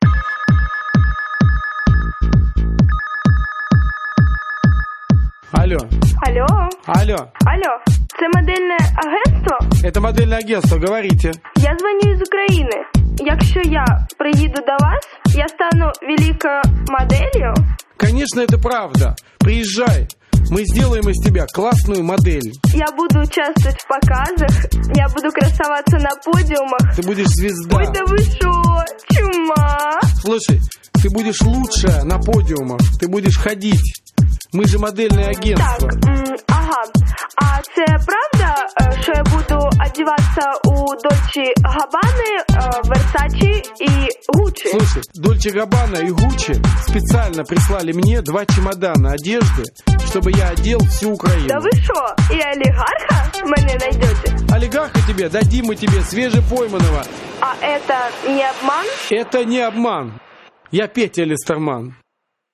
веселые